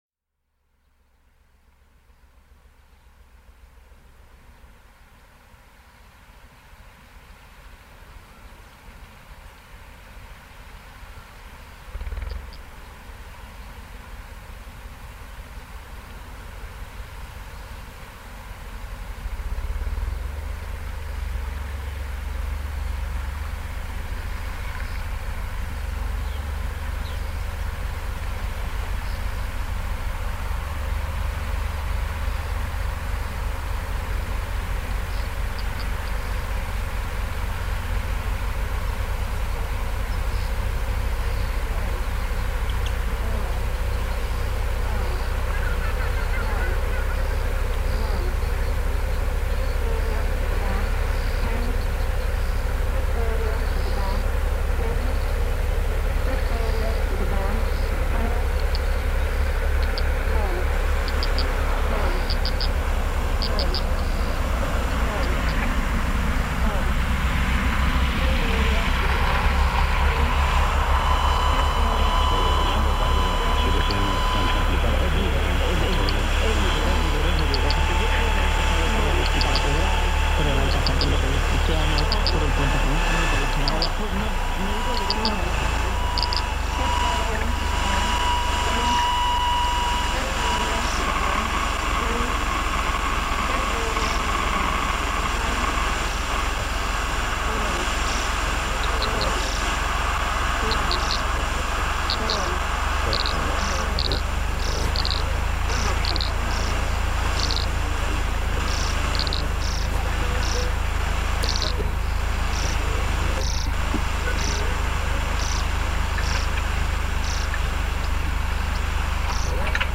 Klein Karoo nature reserve, South Africa